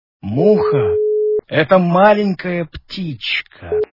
» Звуки » Смешные » Мужской голос - Муха-это маленькая птичка
При прослушивании Мужской голос - Муха-это маленькая птичка качество понижено и присутствуют гудки.
Звук Мужской голос - Муха-это маленькая птичка